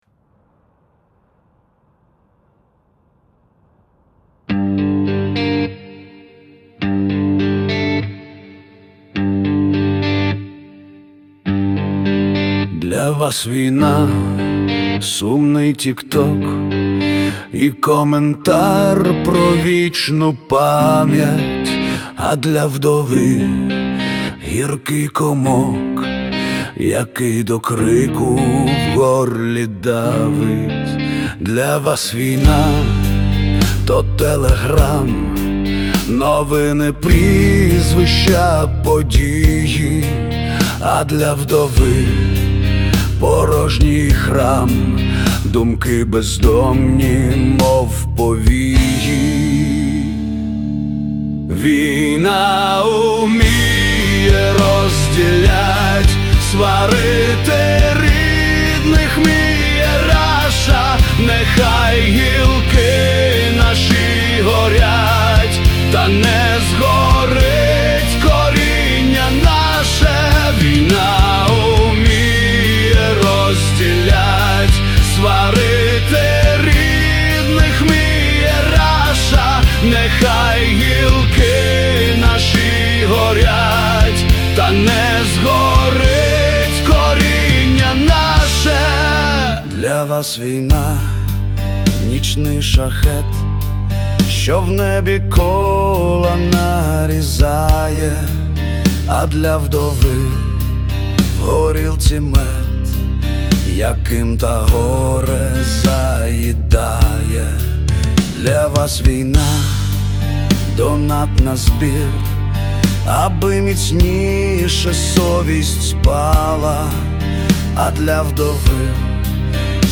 Музика: ШІ.